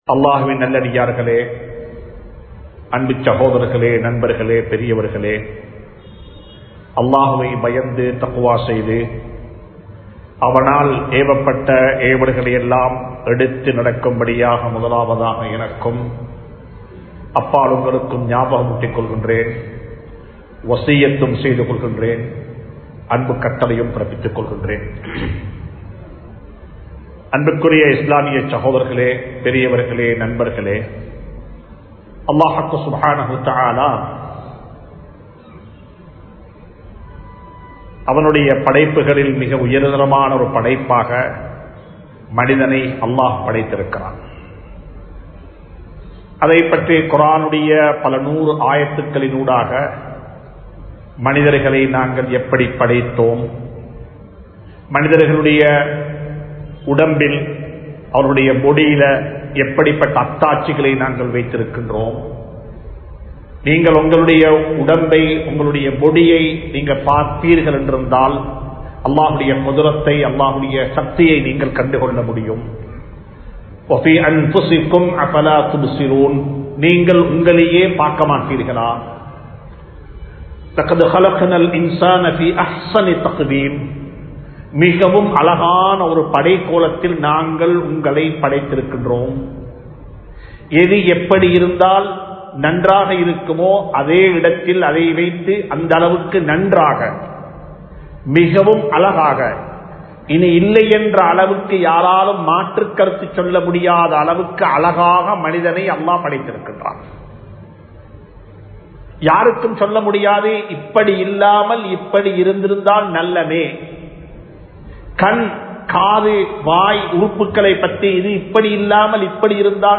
Allahvin Adiyaarhalin Panpuhal (அல்லாஹ்வின் அடியார்களின் பண்புகள்) | Audio Bayans | All Ceylon Muslim Youth Community | Addalaichenai
Majma Ul Khairah Jumua Masjith (Nimal Road)